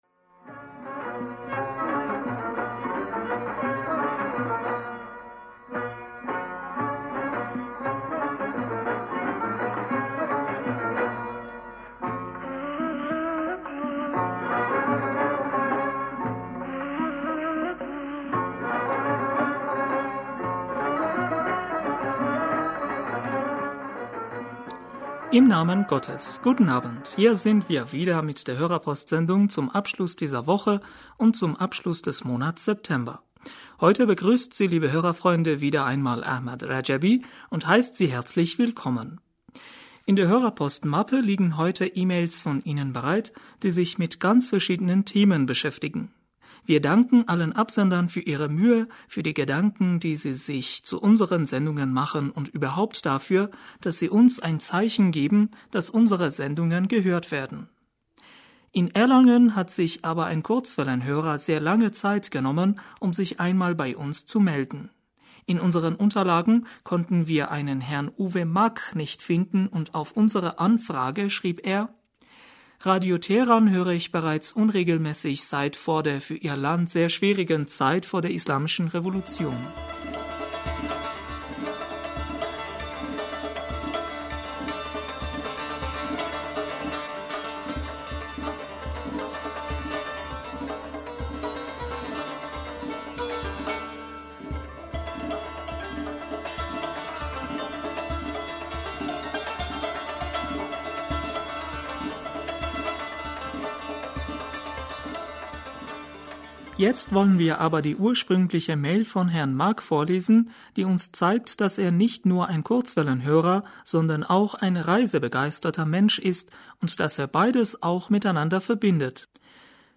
Hörerpostsendung am 25.September 2016 - Bismillaher rahmaner rahim - Guten Abend, hier sind wir wieder mit der Hörerpostsendung zum Abschluß d...